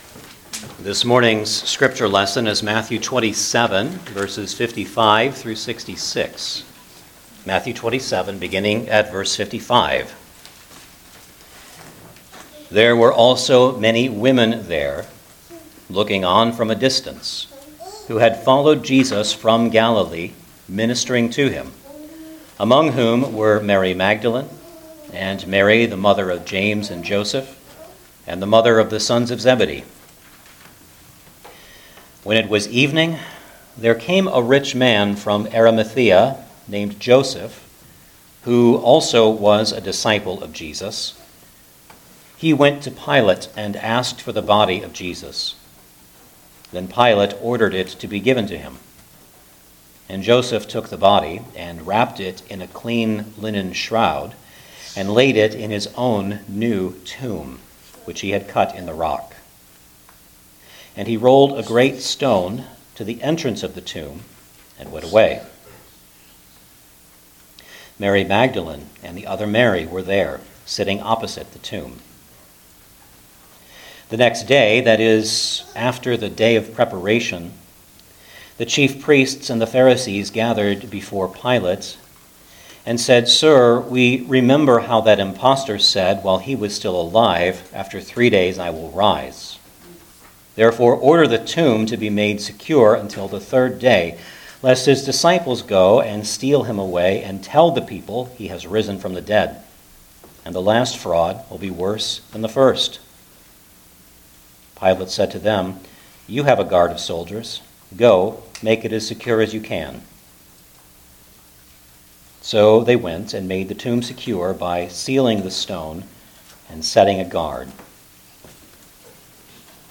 Gospel of Matthew Passage: Matthew 27:55-66 Service Type: Sunday Morning Service Download the order of worship here .